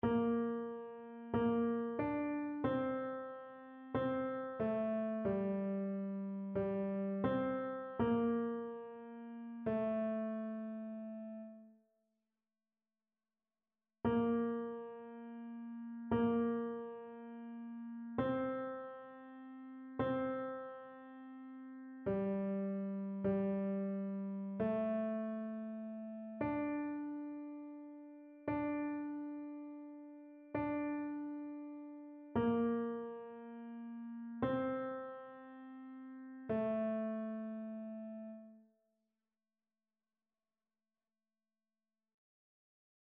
Ténor
annee-abc-temps-du-careme-veillee-pascale-psaume-50-tenor.mp3